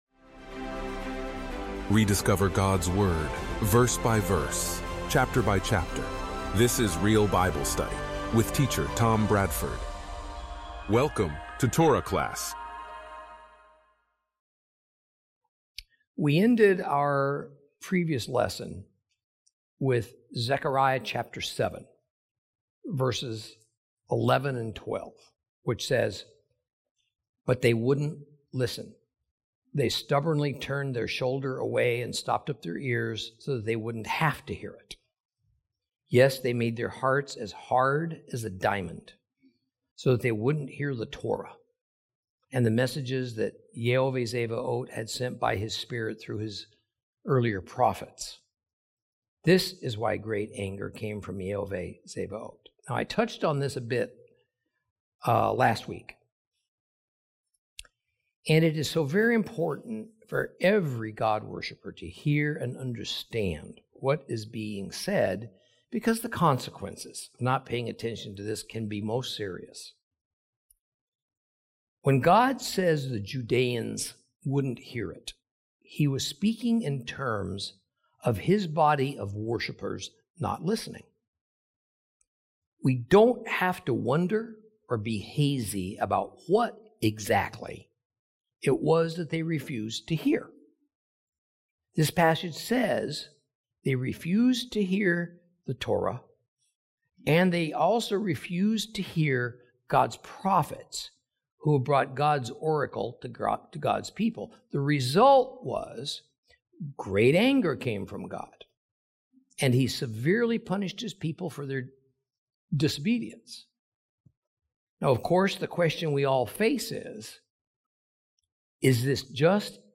Teaching from the book of Zechariah, Lesson 14 Chapters 7 & 8.